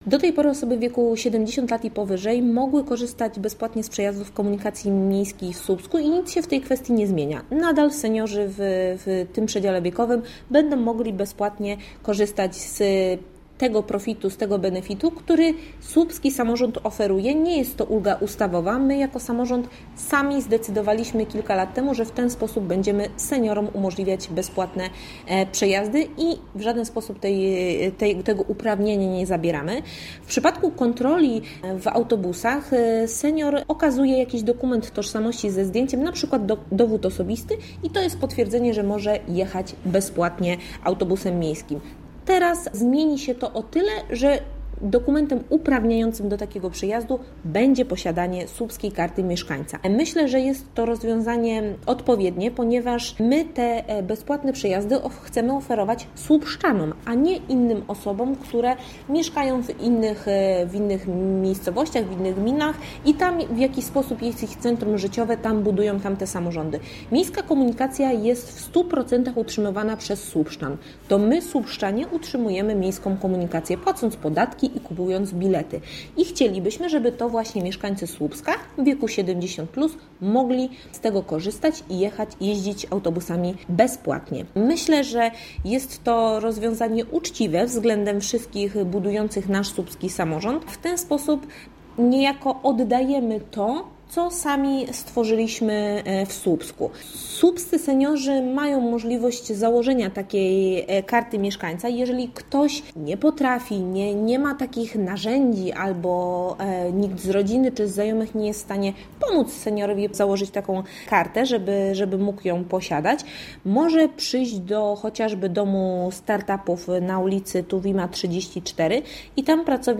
Zapytaliśmy mieszkańców co sądzą na ten temat.